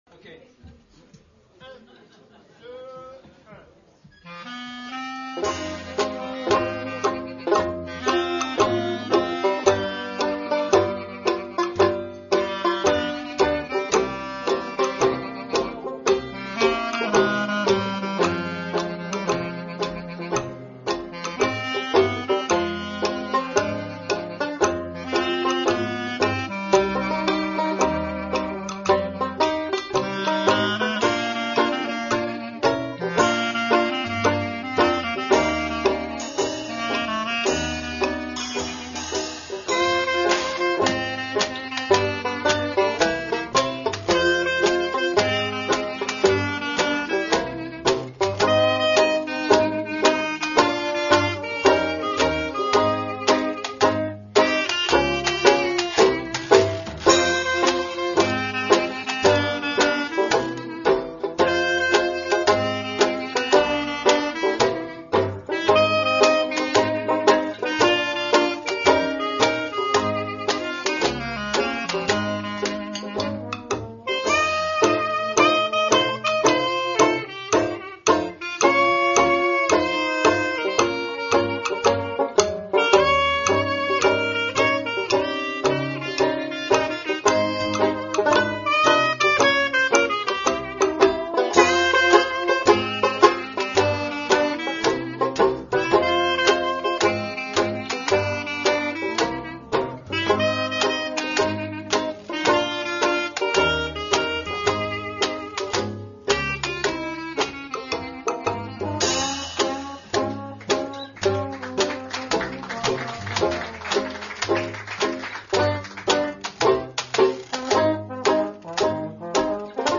Washboard
trombone, vocal
clarinette, sax alto
contrebasse
banjo